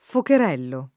vai all'elenco alfabetico delle voci ingrandisci il carattere 100% rimpicciolisci il carattere stampa invia tramite posta elettronica codividi su Facebook focherello [ foker $ llo ] (meglio che fuocherello [ f U oker $ llo ]) s. m.